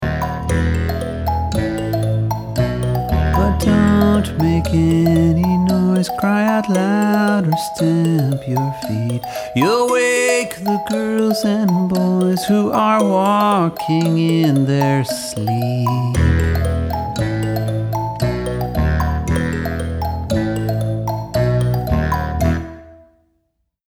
It's a kid's album.
but with a more simple, gentle approach
They have a gift for melody that can be enchanting.